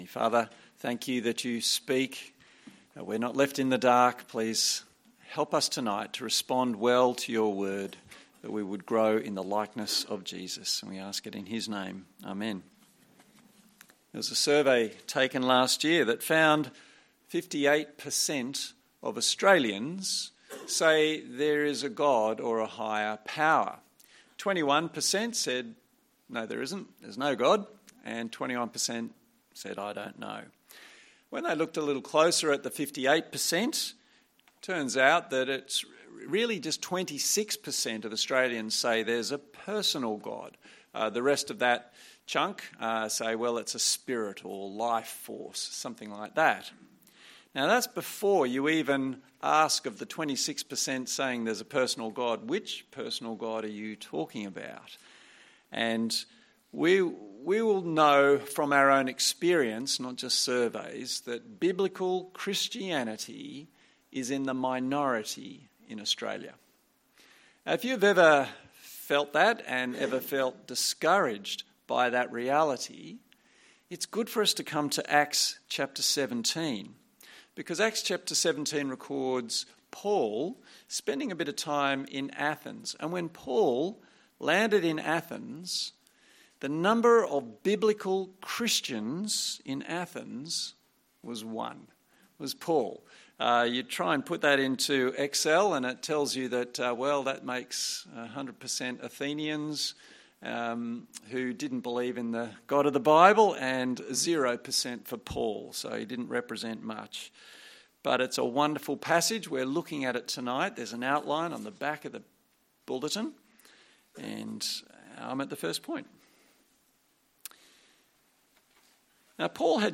Preacher
Acts Passage: Acts 17:16-34 Service Type: Sunday Evening Download Files Notes « God’s Word Mightily Advances…